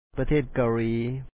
pathèet kawlǐi  Karea